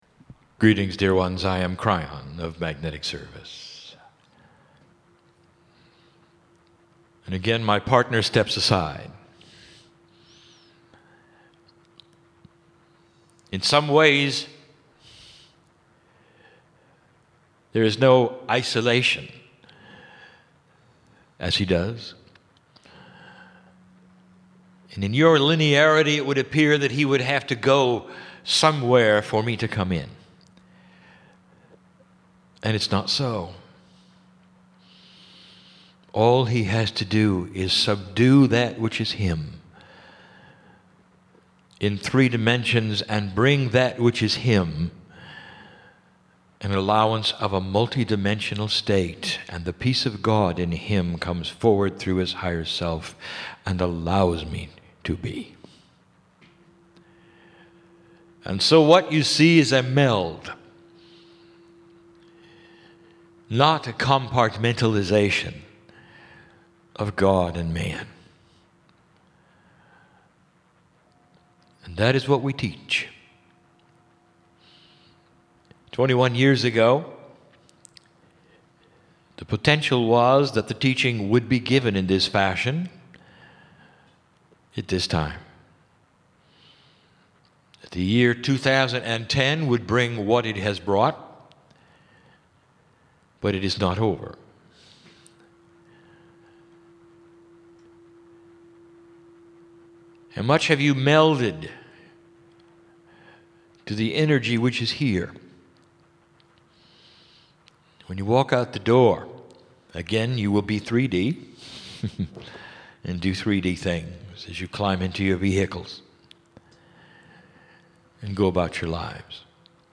Live Channel for Kryon 47 megabytes FILE IS NAMED
28 minute channelling session
INSTRUCTIONS: 47 megabytes 28 minutes High-quality Stereo - MP3 Filename: "Dallas_2_2010.mp3" PC - Right-click the left image to download the file.